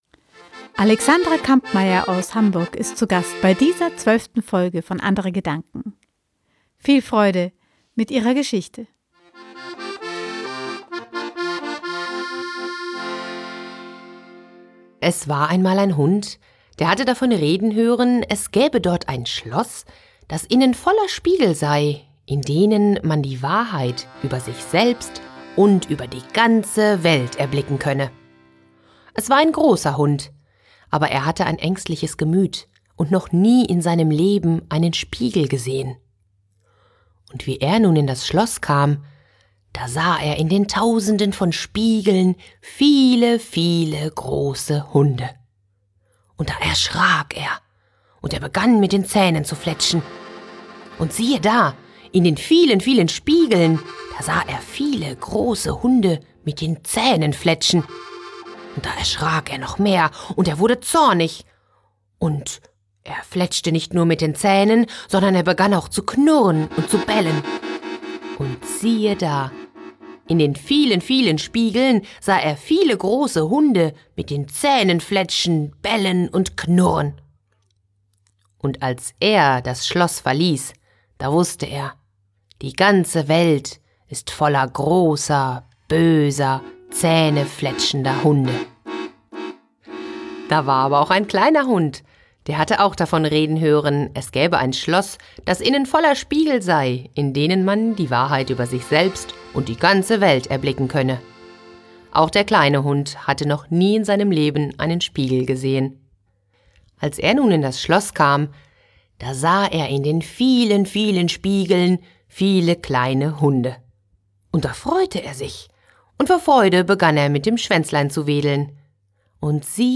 frei erzählte Geschichten, Musik, Inspiration